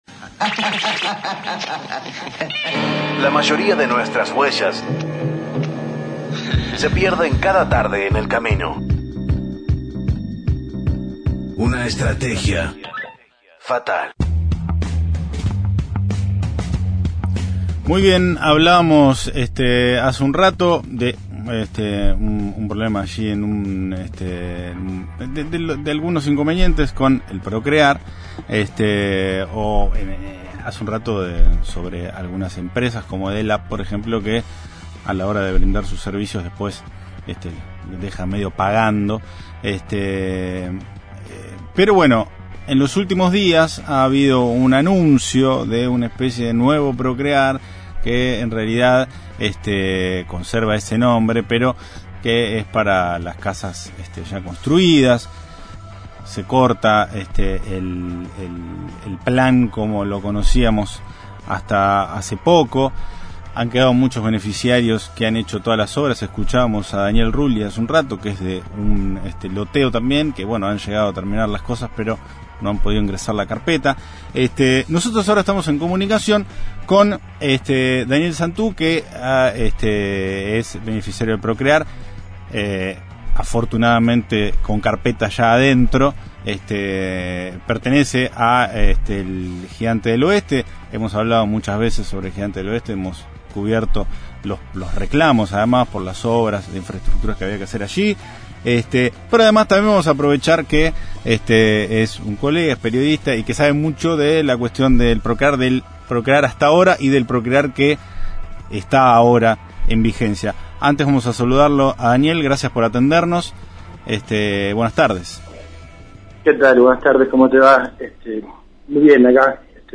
A diferencia de los vecinos de Arana, que ni siquiera pudieron comenzar con las obras antes de la sustitución del crédito, los vecinos de Gigante del Oeste están en la etapa final de las construcciones. Conversamos con uno de sus beneficiarios